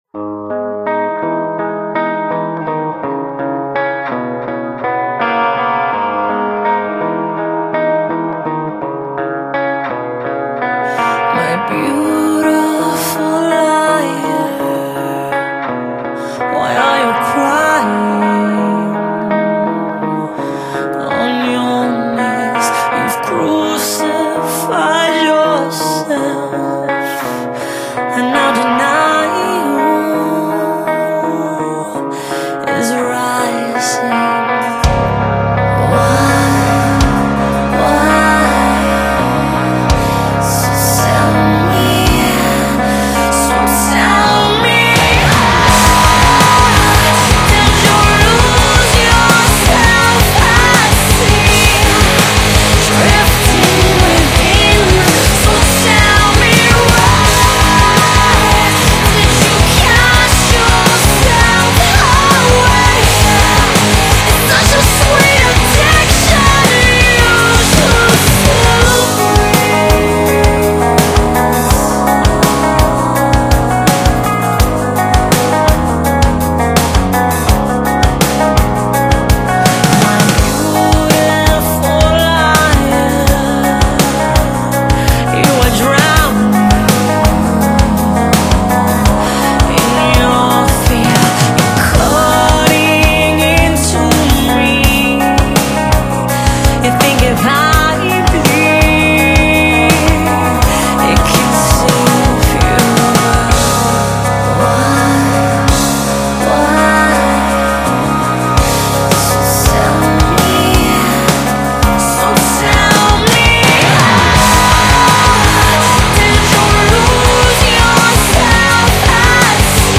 еще одна колыбельная)))ну,для металистов)))